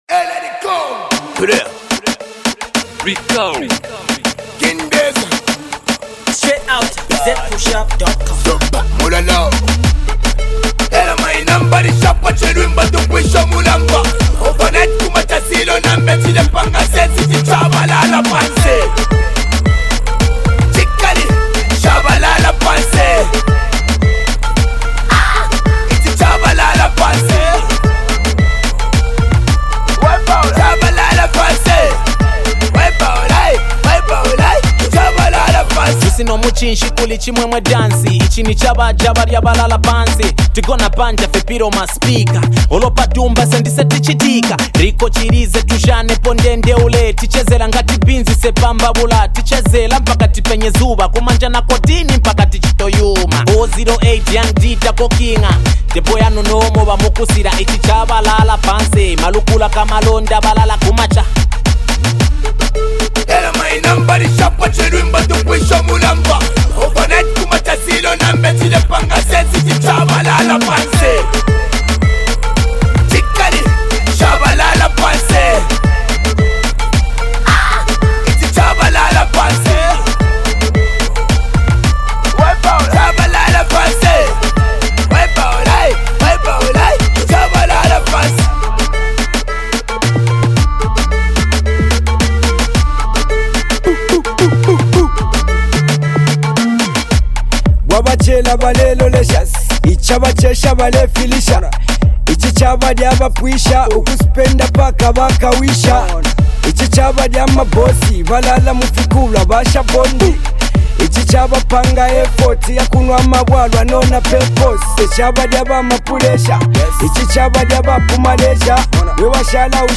meant for dancing